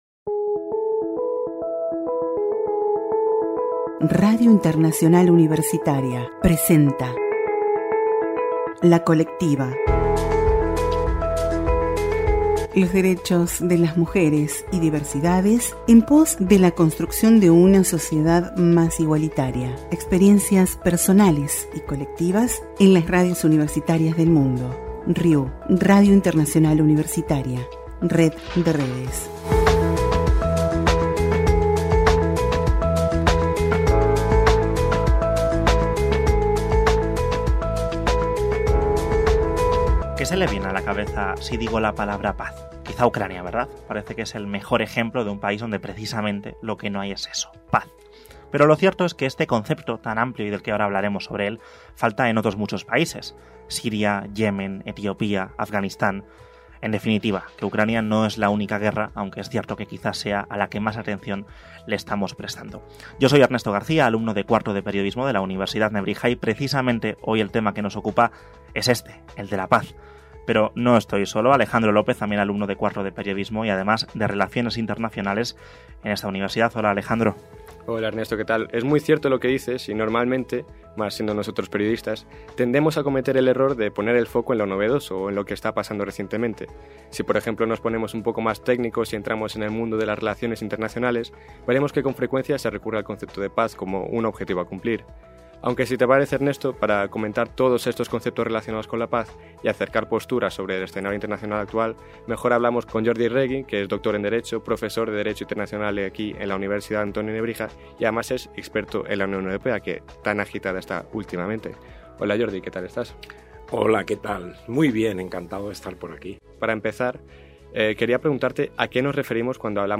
La paz puede ser permanente o no y nos preguntamos qué papel que juegan en ello las instituciones. Una entrevista